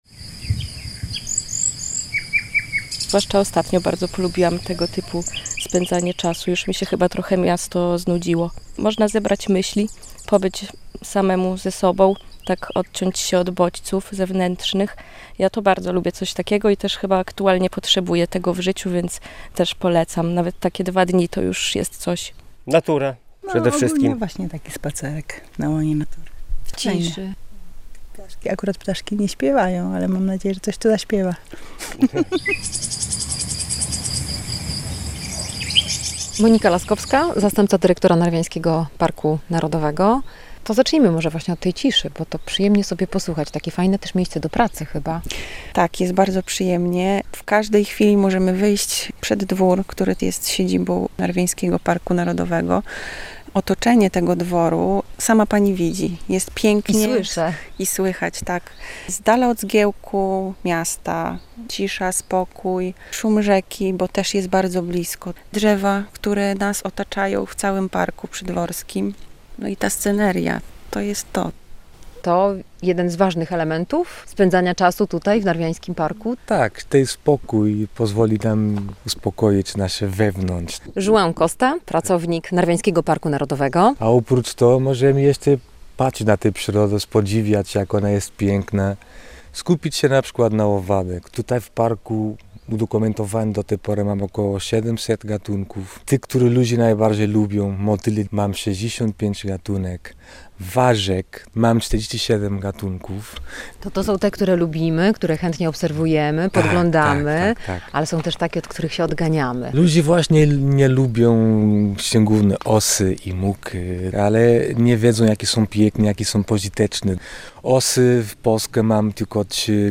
W Narwiańskim Parku Narodowym można odpocząć od miejskiego zgiełku - relacja